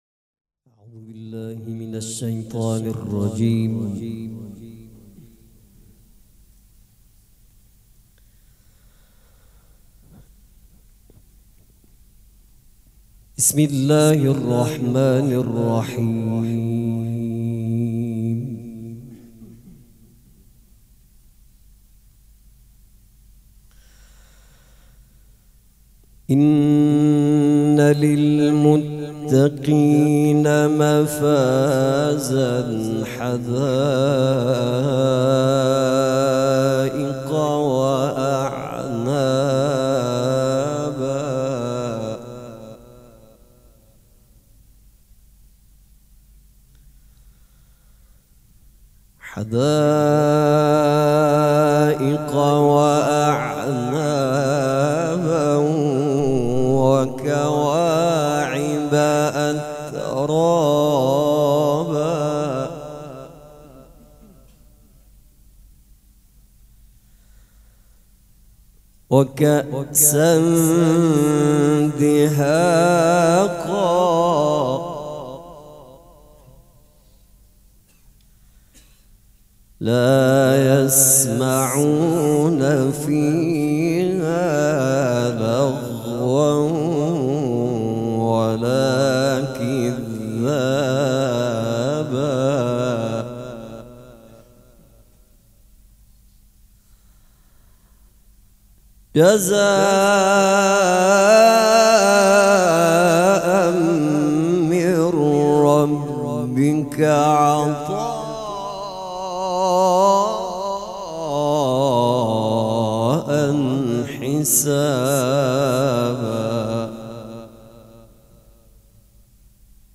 قرائت قرآن
هیئت ریحانه الحسین سلام الله علیها